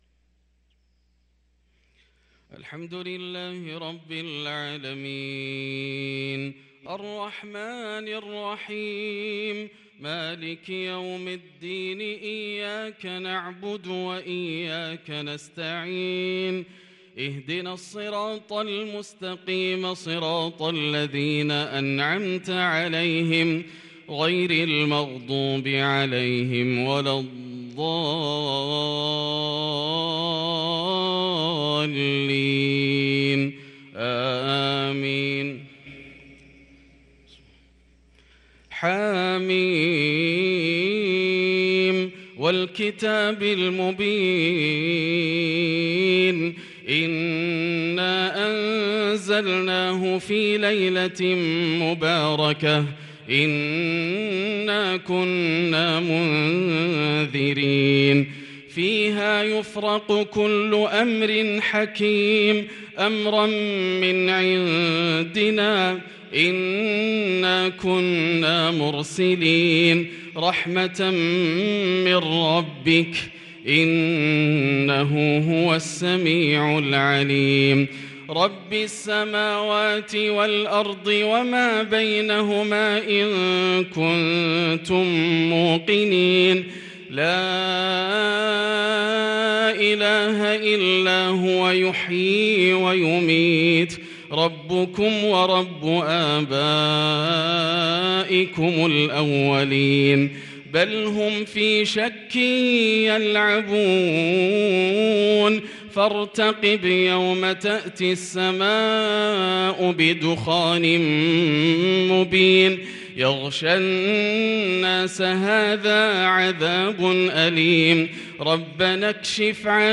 صلاة الفجر للقارئ ياسر الدوسري 25 شعبان 1443 هـ
تِلَاوَات الْحَرَمَيْن .